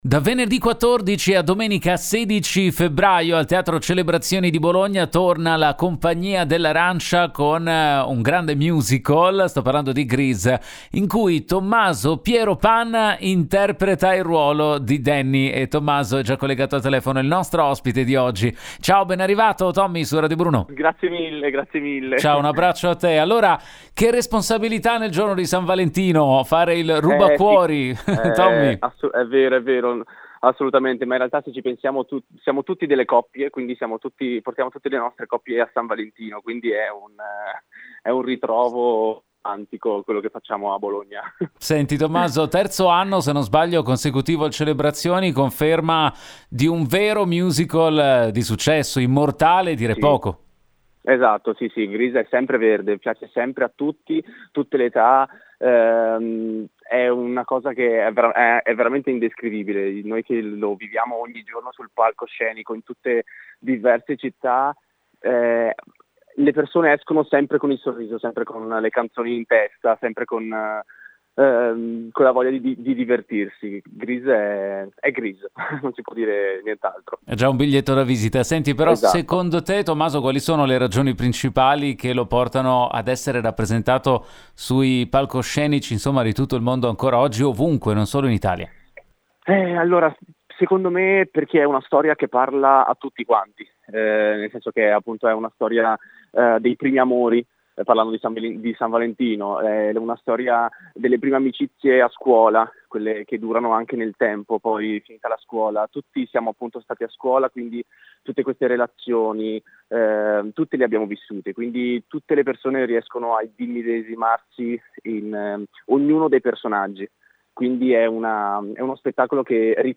Home Magazine Interviste Grease torna in scena al Celebrazioni di Bologna